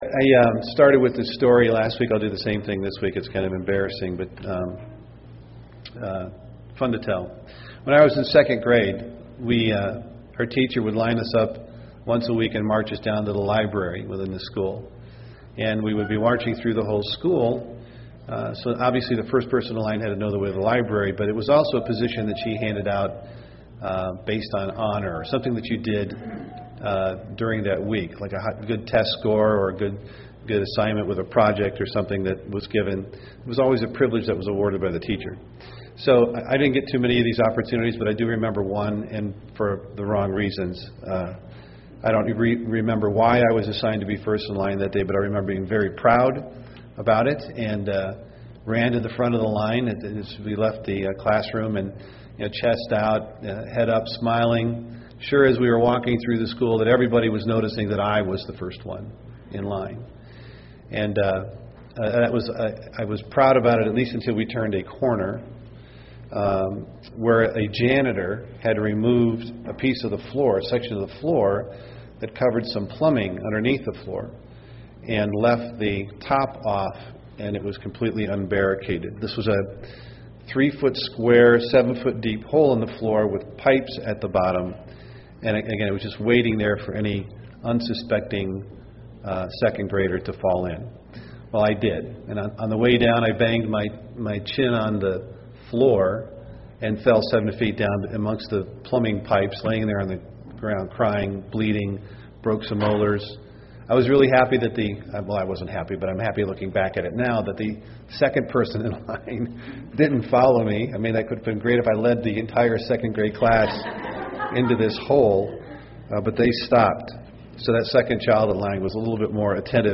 Print The privilege and responsibility of being the first born UCG Sermon Studying the bible?